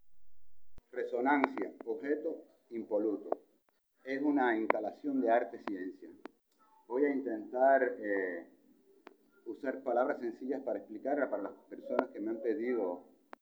If someone has the time to listen to it, the sound is this loud popping mic or electric noise in the first half of the audio: it repeats 21 times in the first minute of his recording and then disappears. Sometimes is louder and sometimes lower…
It sounds like handling-noise.
As others have said, it sounds like something is tapping against the mic / phone.
The recording has lots of discontinuities.